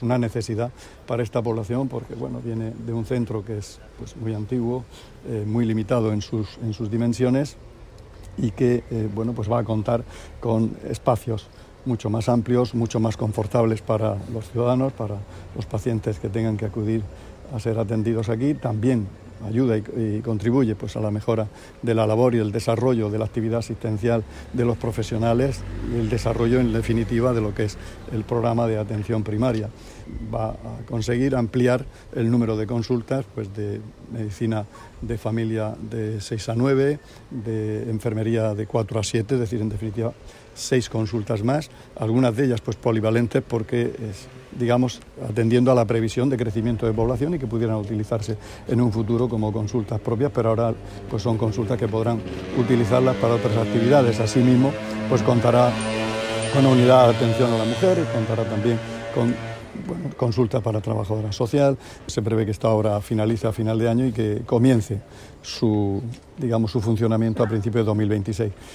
Sonido/ Declaraciones del consejero de Salud sobre el nuevo centro de salud de Lorquí.